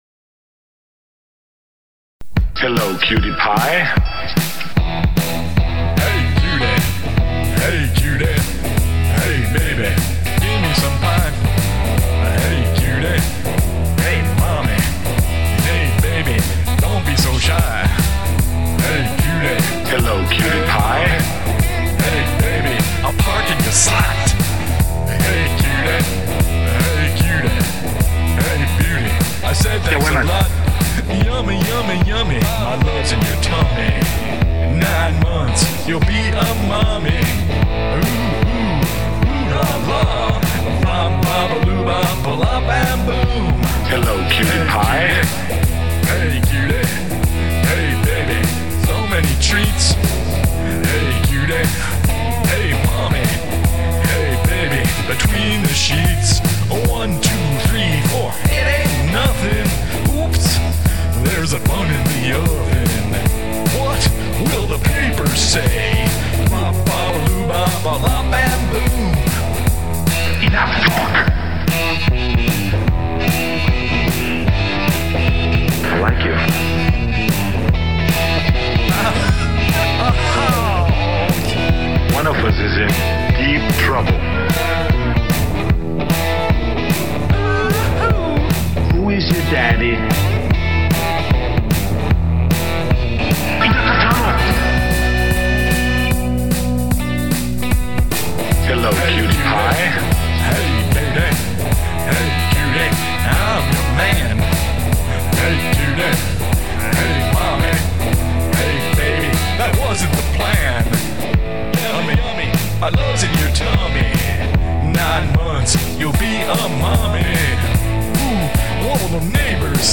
You can hear a real Les Paul here in the vid of Cursing the Oilmen (the tone is early Cream although Clapton was more closely associated with the Gibson SG at that point) and here in